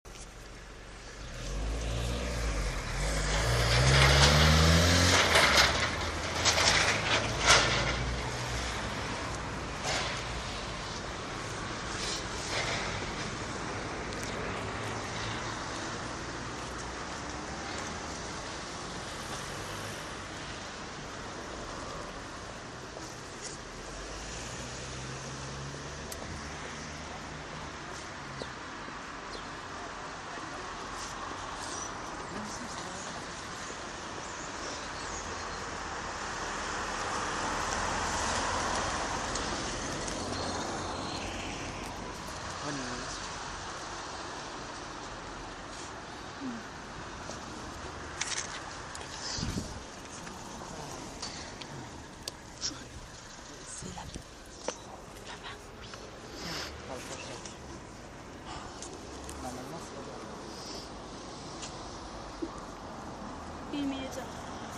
Bruits de camions et voitures